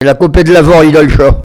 Olonne-sur-Mer
locutions vernaculaires